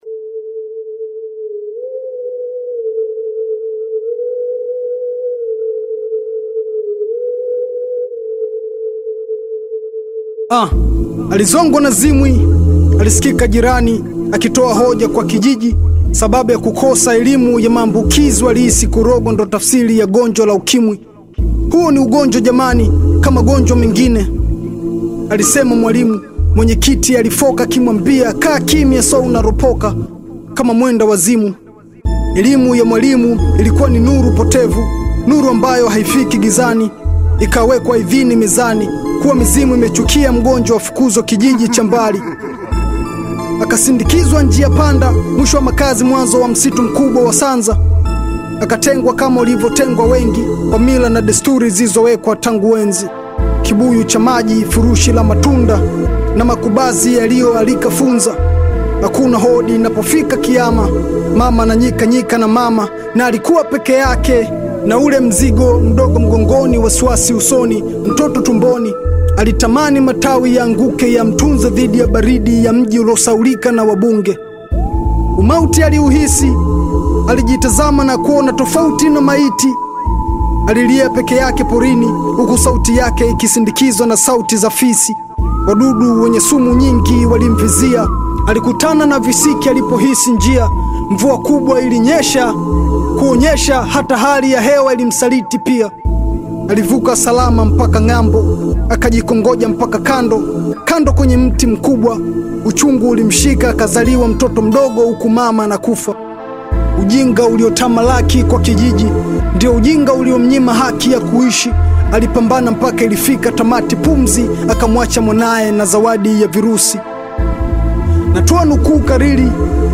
Tanzanian Bongo Flava artist, singer and songwriter
hip-hop story song